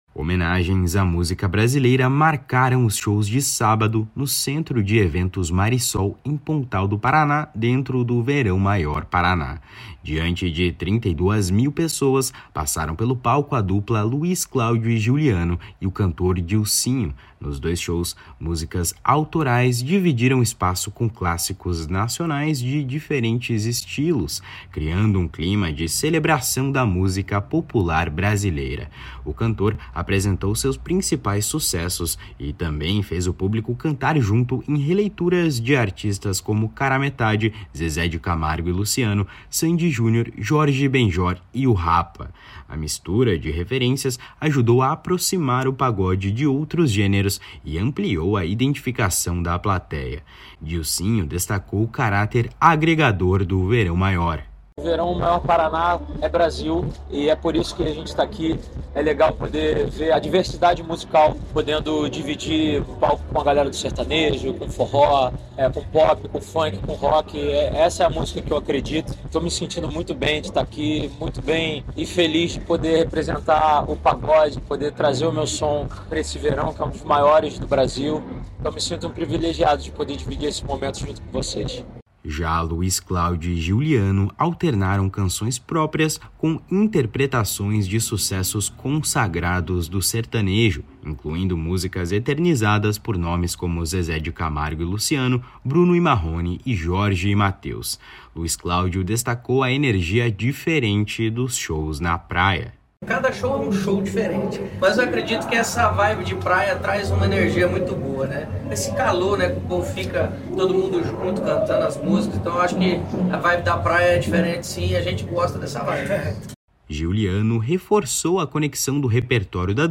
// SONORA LUIZ CLÁUDIO //
// SONORA GIULIANO //